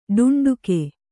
♪ ḍuṇḍuke